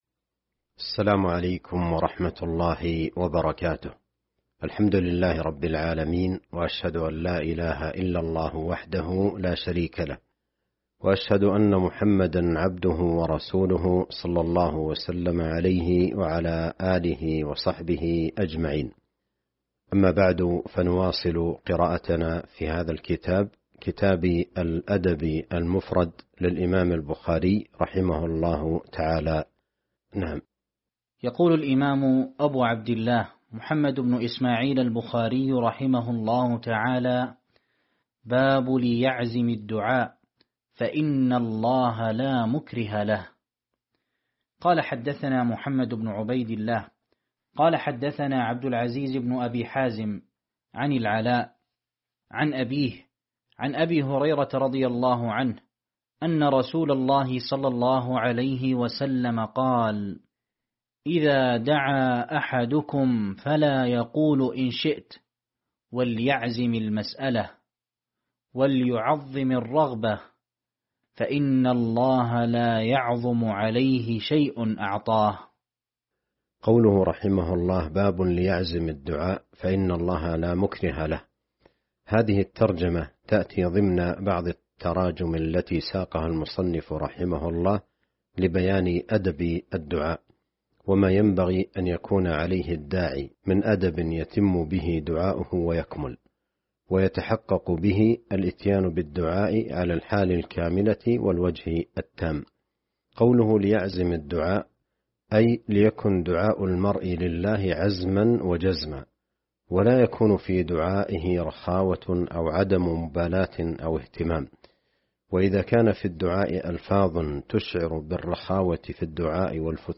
شرح الأدب المفرد الدرس 191 باب لِيَـعْـزِم الدعـاء فـإن الله لا مُـكْـرِهَ له